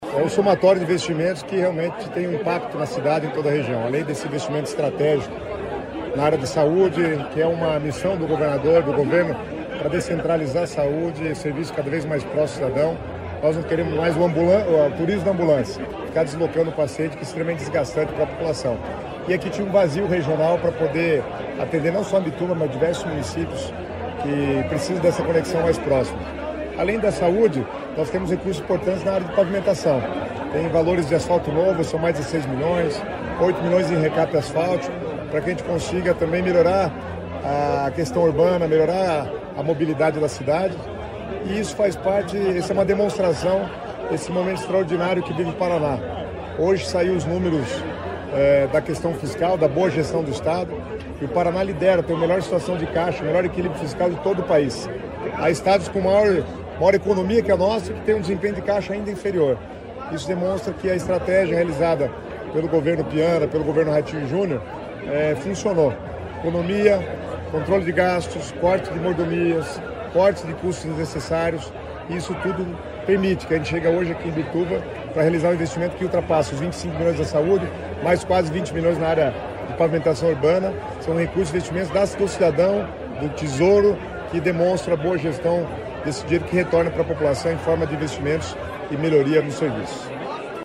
Sonora do secretário Estadual das Cidades, Guto Silva, sobre a autorização da obra do Hospítal Municipal de Imbituva